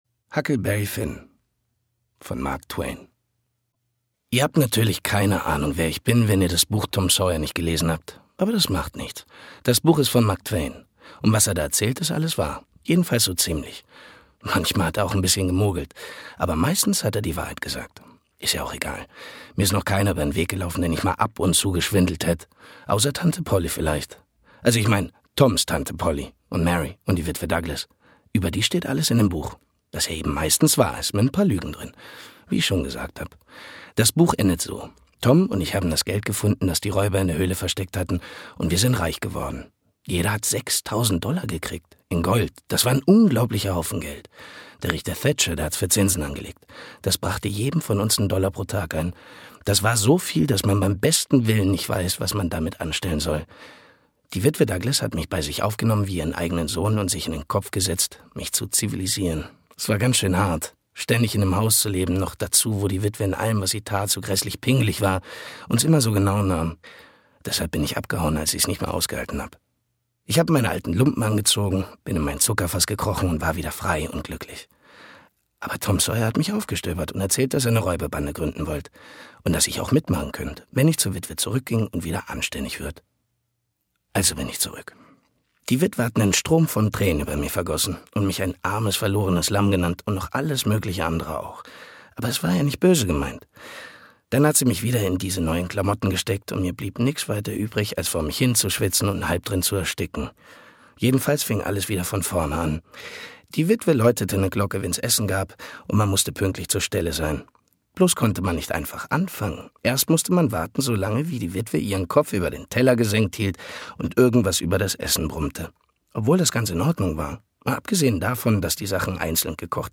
Ken Duken (Sprecher)